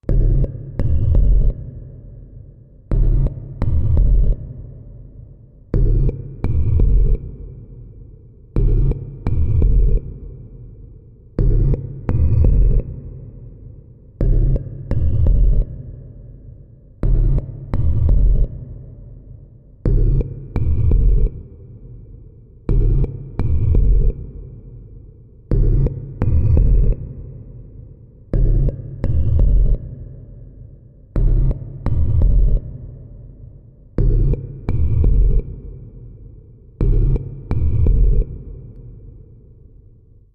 Alien Heartbeat, Machine, Alien Heartbeat, Pulsing, Repeating Beat